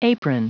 Prononciation du mot apron en anglais (fichier audio)
Prononciation du mot : apron